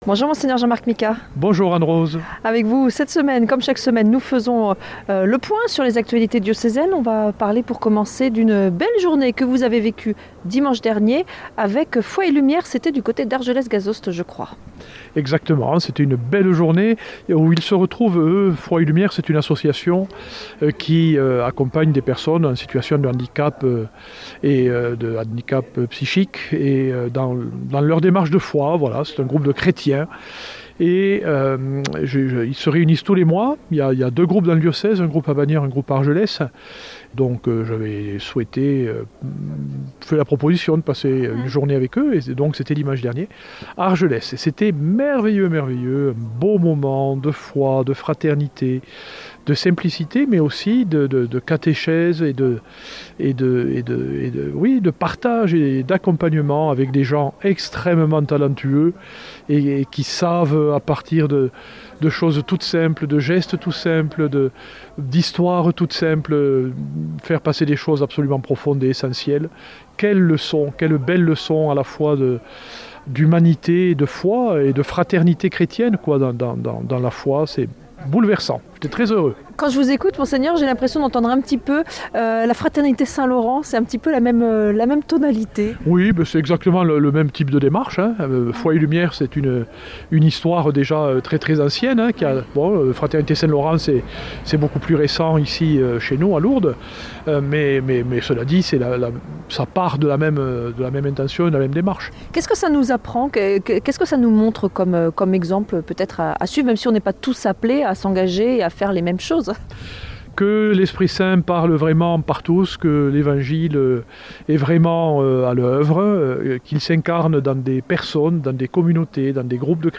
vendredi 20 mars 2026 Entretien avec Mgr Micas - Évêque de Tarbes Lourdes Durée 12 min